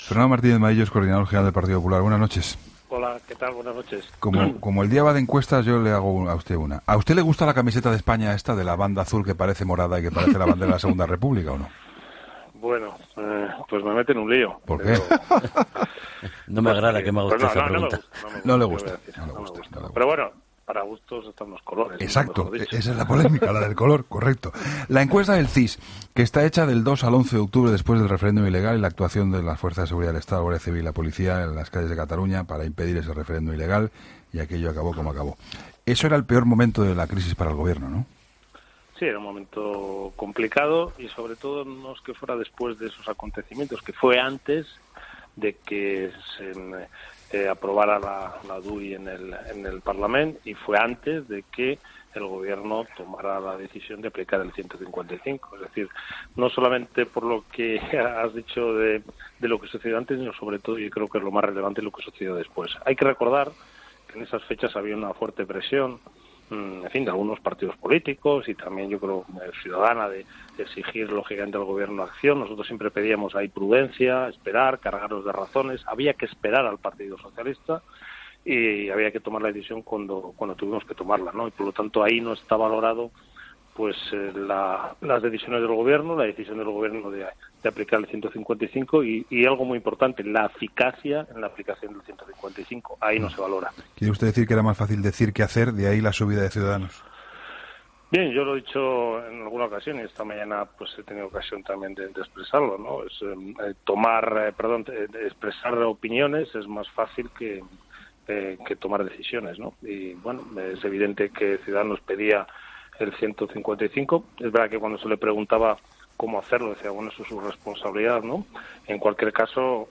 El vicesecretario de Organización del PP, Fernando Martínez-Maíllo, ha analizado en 'La Linterna' con Juan Pablo Colmenarejo, los resultados del...